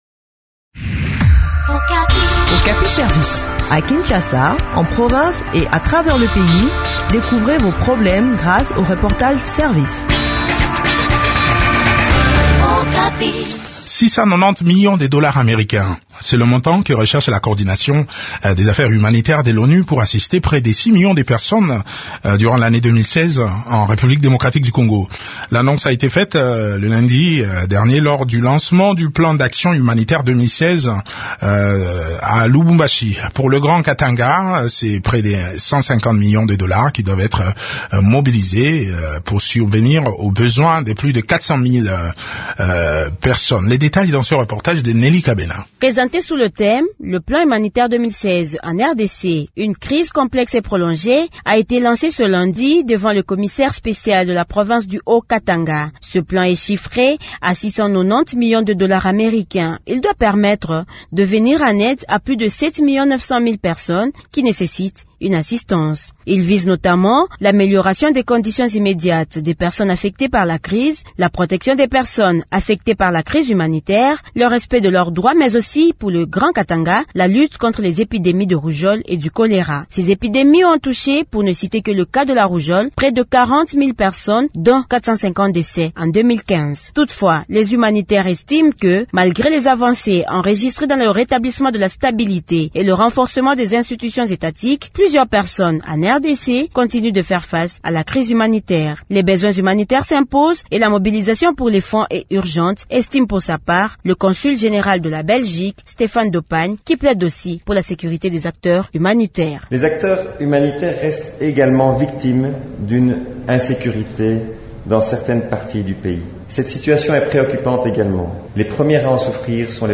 Les grandes lignes de ce plan d’action humanitaire dans cet entretien